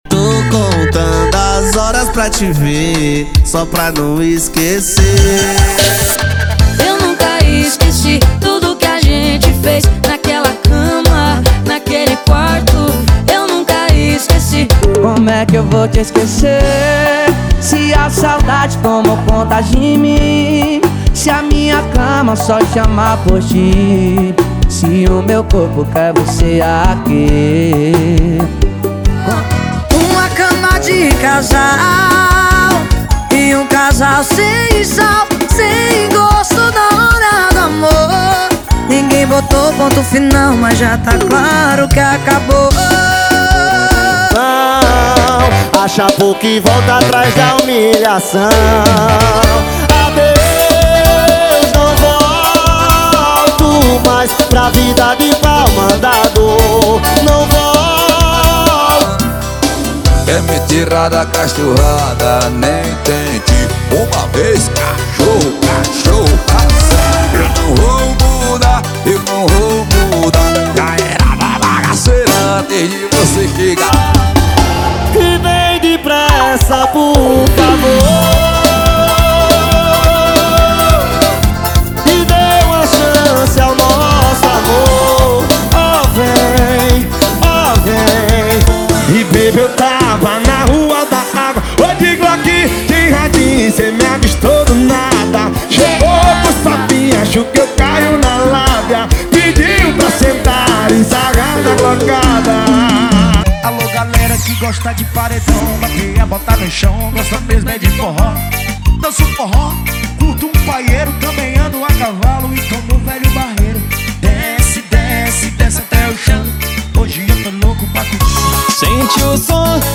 • Em Alta Qualidade